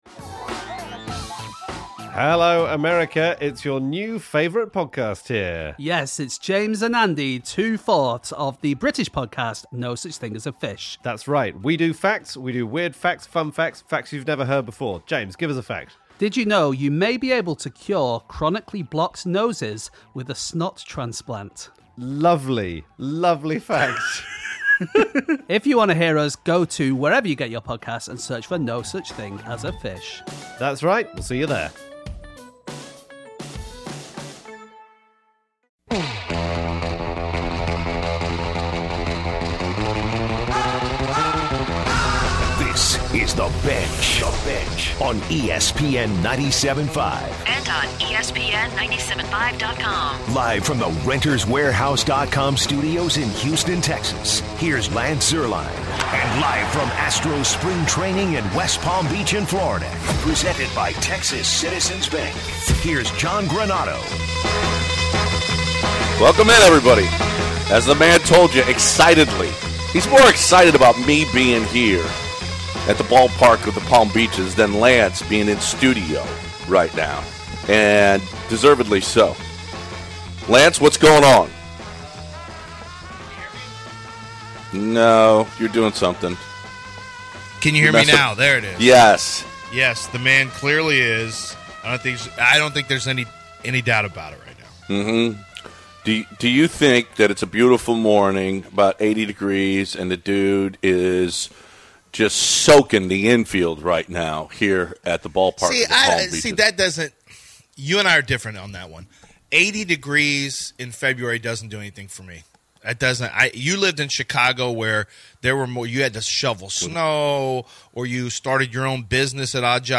Chris Devenski interview: Discussed Devo’s new beard.
Live from West Palm Beach.
Jim Crane calls in.